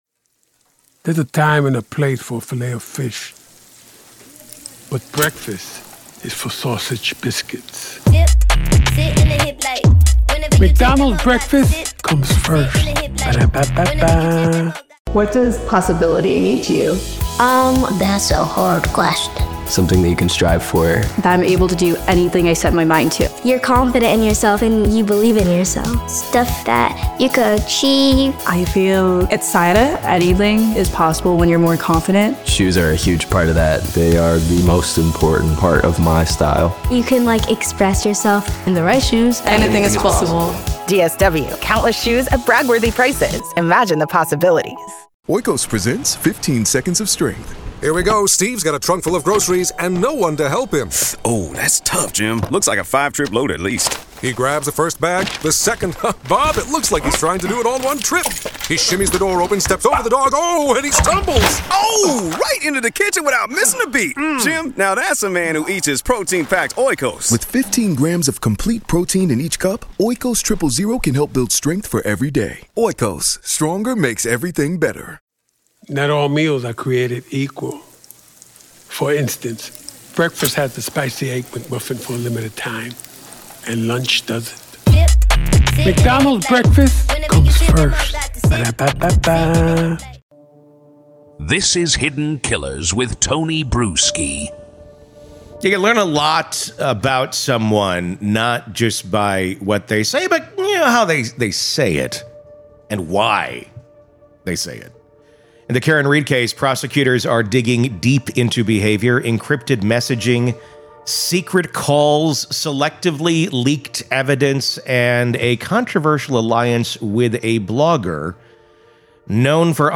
True Crime Today | Daily True Crime News & Interviews / Was Karen Read Exposing Bad Police Work Or Destroying Lives Through A Blogger To Protect Herself?